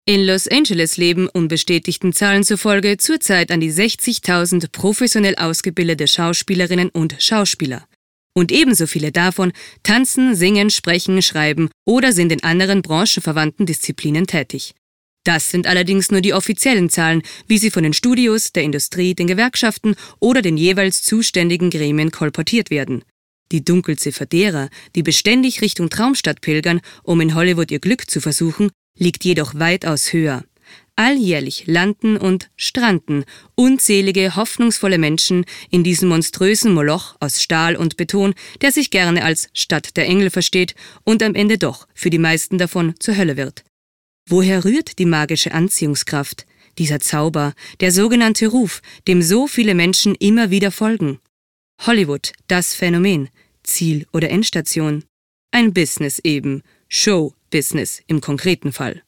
Sie suchen eine Sprecherin für verschiedenste Aufnahmen?
“Los Angeles” – Reportage